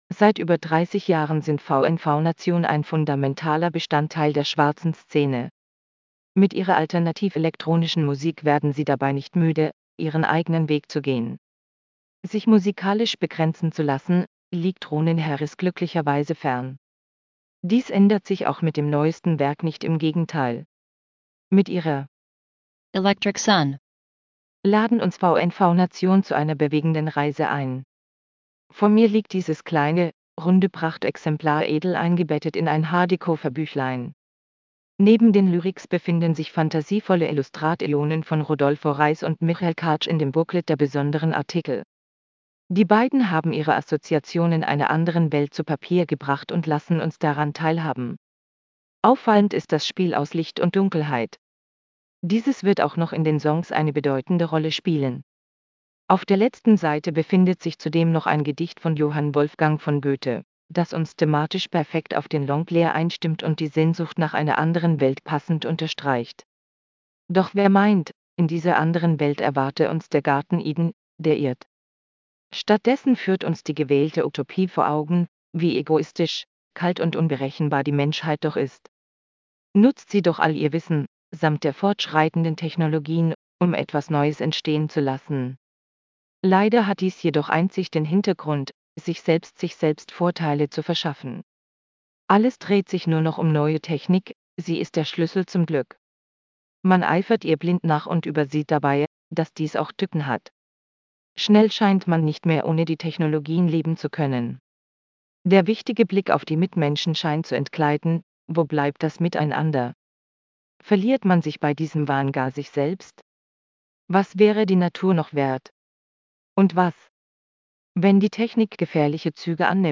Lass Dir den Beitrag vorlesen: /wp-content/TTS/163607.mp3 Mit ihrer Electric Sun laden uns VNV Nation zu einer bewegenden Reise ein.